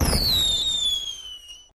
audio: Converted sound effects
firework_whistle_02.ogg